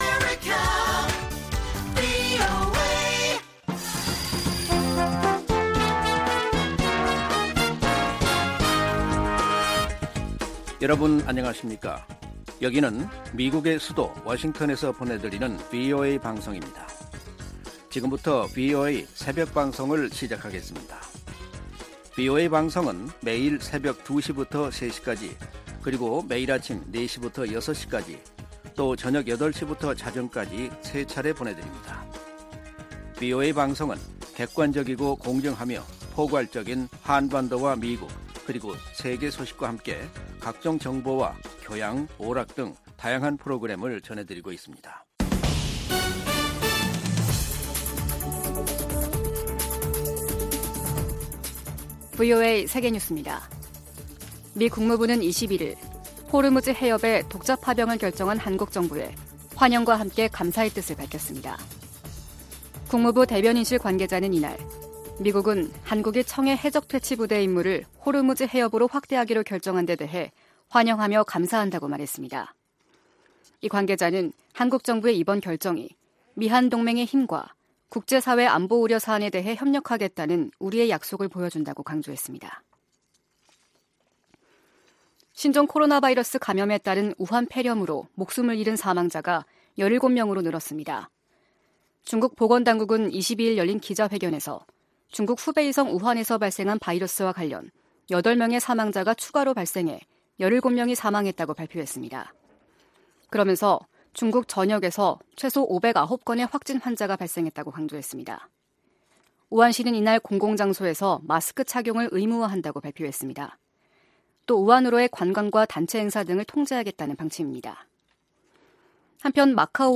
VOA 한국어 '출발 뉴스 쇼', 2020년 1월 23일 방송입니다. 제네바주재 미국 군축담당 대사는 북한이 핵과 탄도미사일 실험 중단 약속을 파기할 가능성을 언급한데 우려를 나타냈습니다. 미국 정부는 한국 정부의 호르무즈해협 파병 결정에 환영의 뜻을 나타냈습니다.